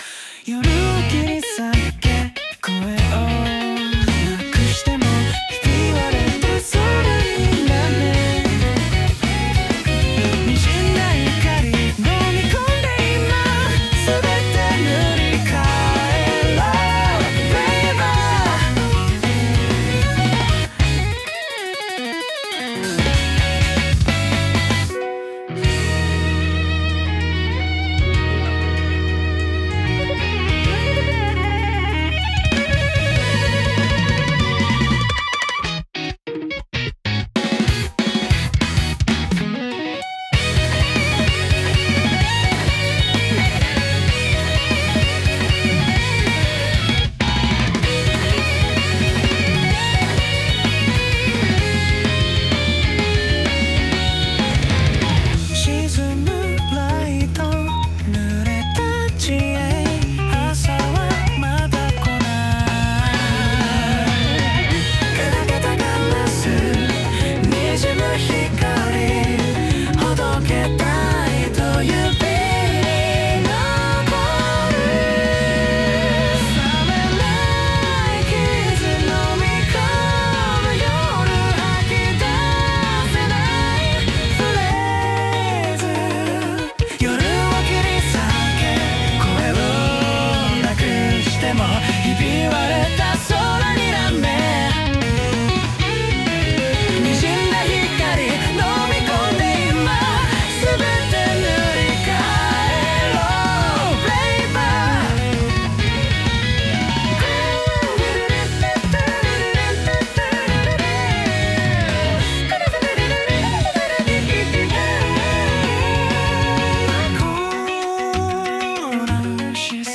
男性ボーカル
イメージ：J-ROCK,男性ボーカル,かっこいい,クール,スタイリッシュ,複雑,シューゲイザー